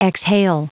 Prononciation du mot exhale en anglais (fichier audio)
Prononciation du mot : exhale